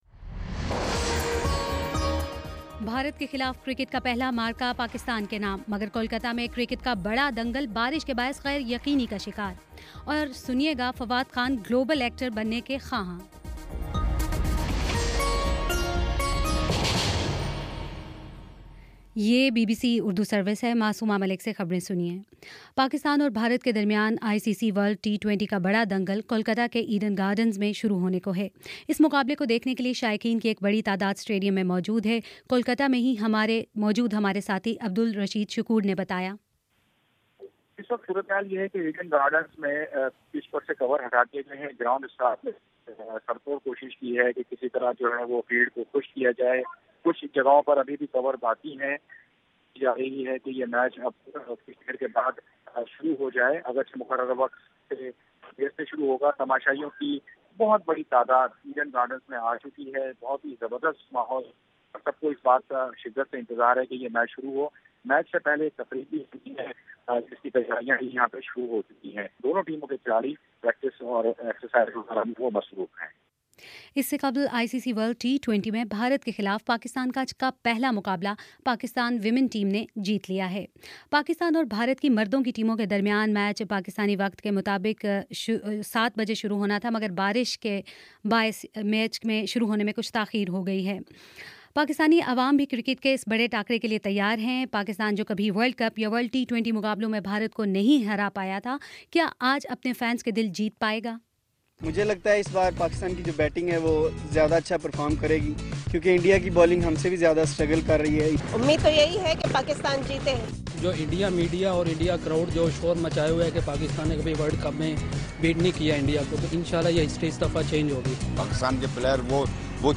مارچ 19: شام سات بجے کا نیوز بُلیٹن